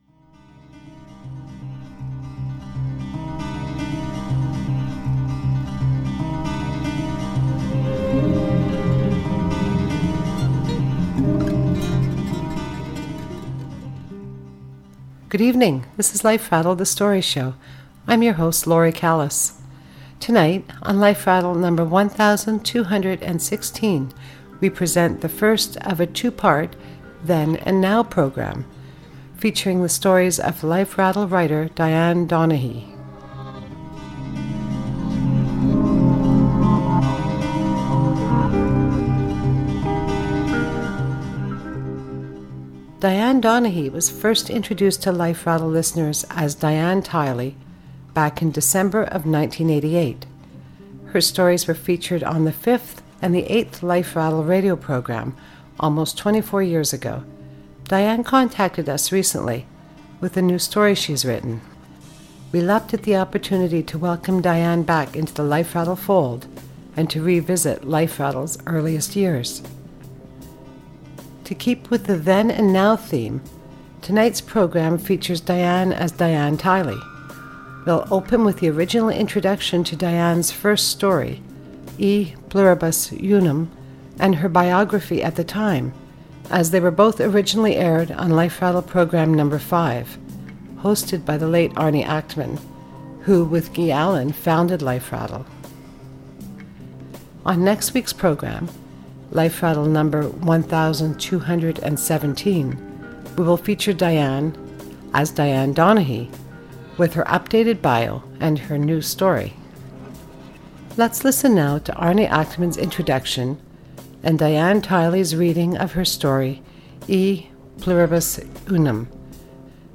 reading her stories